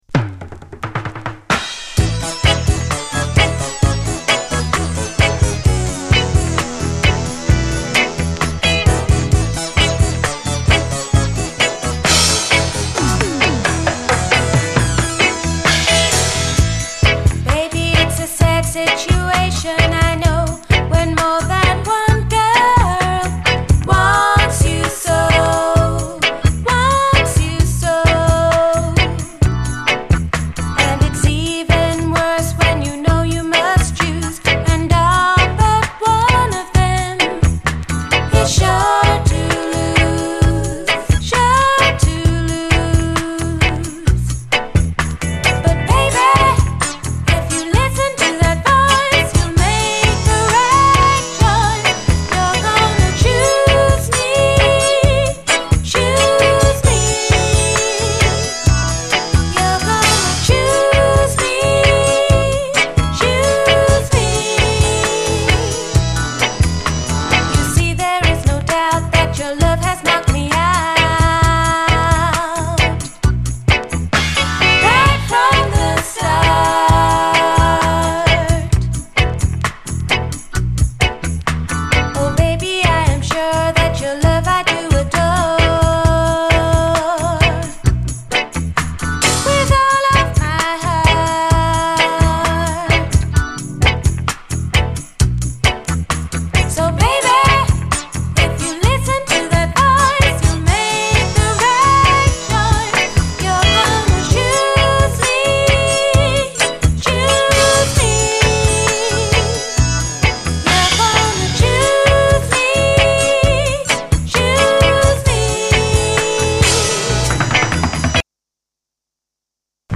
REGGAE
全編最高のUKラヴァーズ名盤！